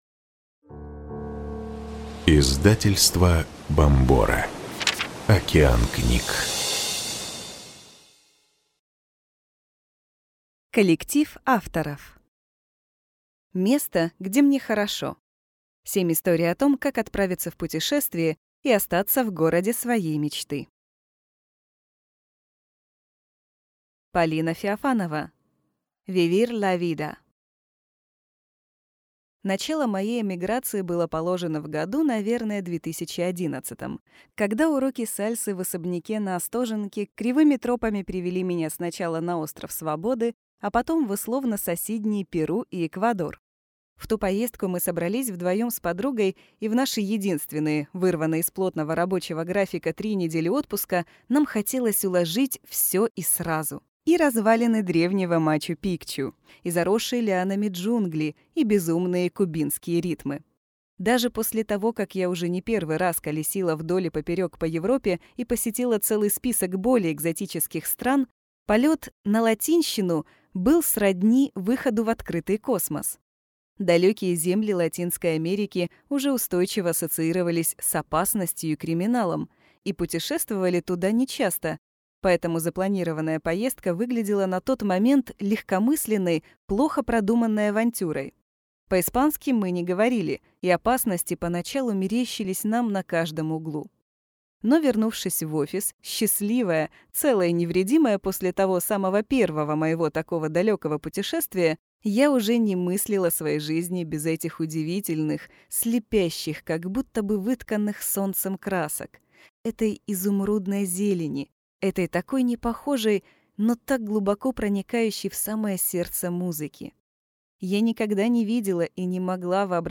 Аудиокнига Место, где мне хорошо. 7 историй о том, как отправиться в путешествие и остаться в городе своей мечты | Библиотека аудиокниг